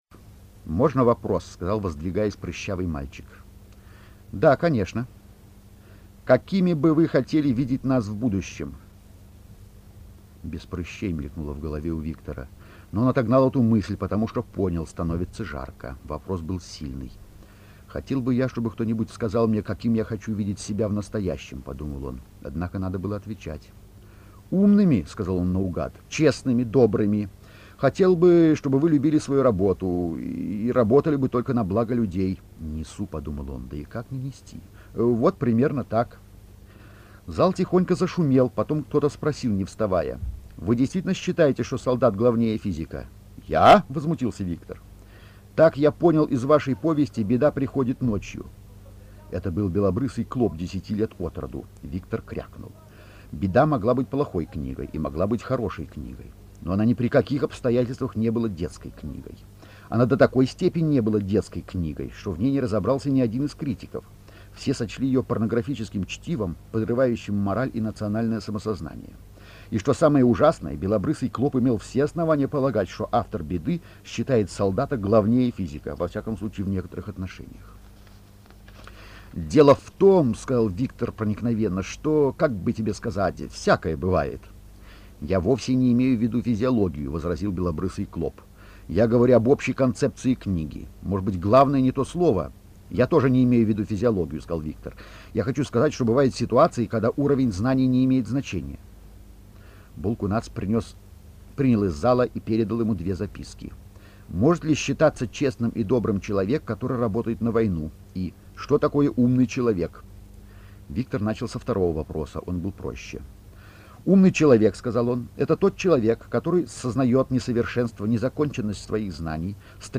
А.Стругацкий читает отрывок из книги Гадкие лебеди 1971